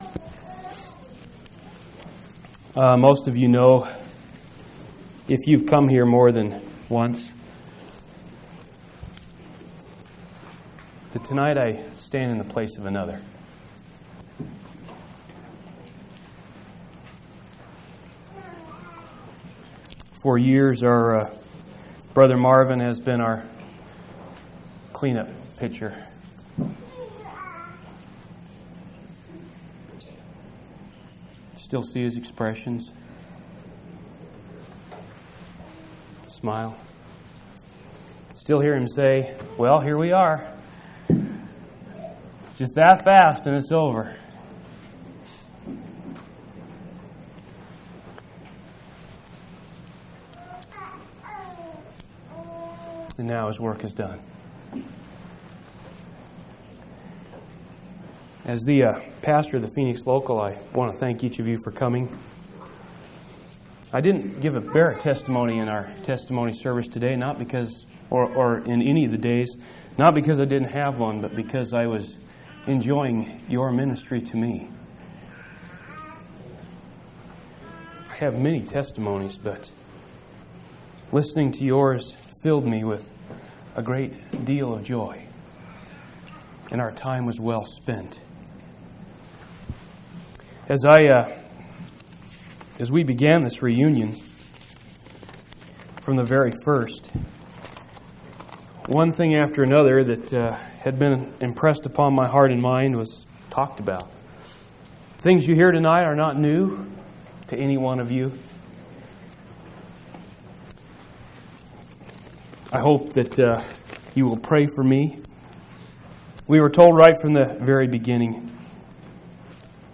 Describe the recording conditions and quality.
11/29/1998 Location: Phoenix Reunion Event